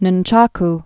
(nən-chäk) or nun·chuck (nŭnchŭk) or num·chuck (nŭm-)